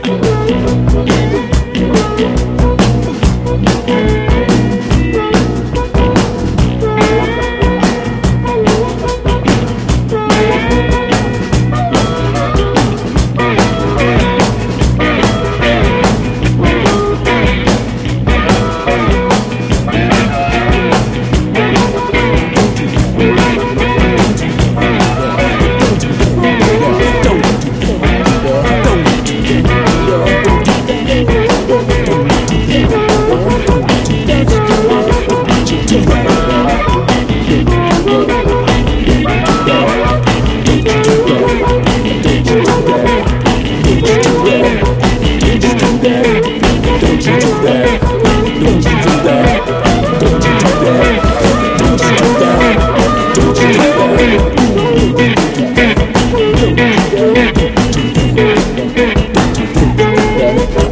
ROCK / 90''S～ / INDIE POP / 90'S / LO-FI / INDIE (US)
エモ/ポストロック好きにも響く轟音スペイシー・ファズ・ローファイ・バンド！